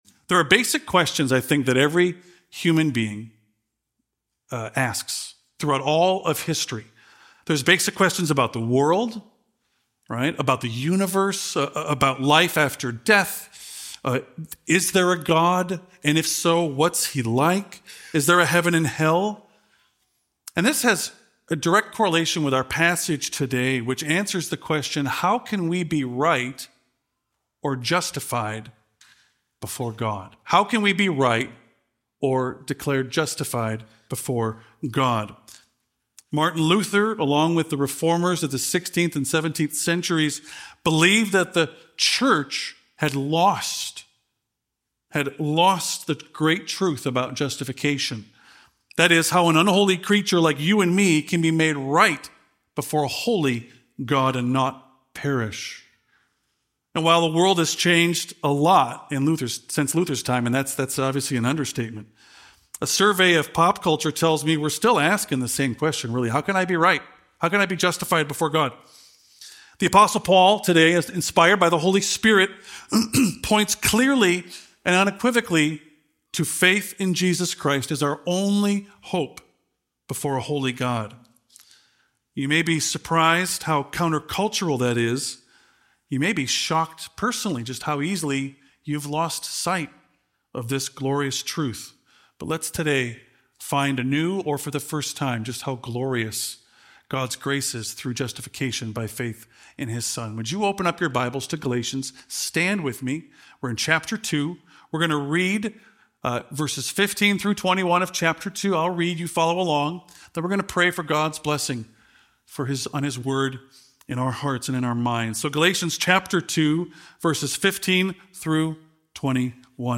With clarity and conviction, this sermon explores what it means to live by faith, be united with Christ, and receive God’s grace as a gift, not a reward.